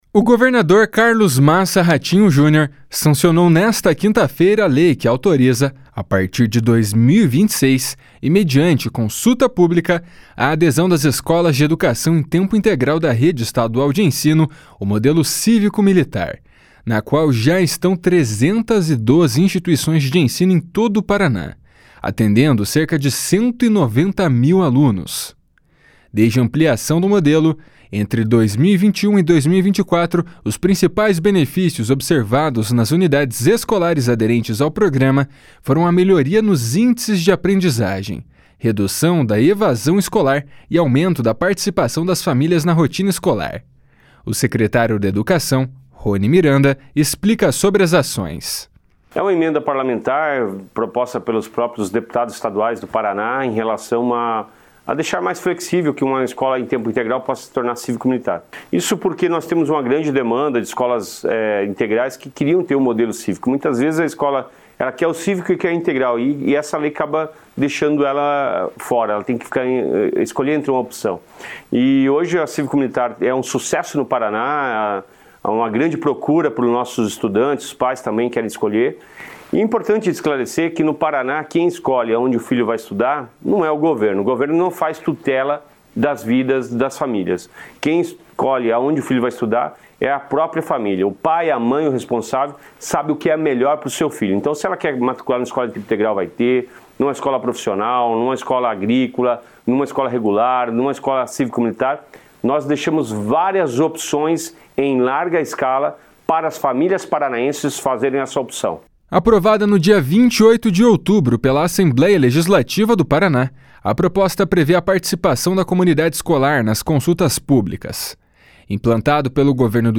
O secretário da Educação, Roni Miranda, explica sobre as ações.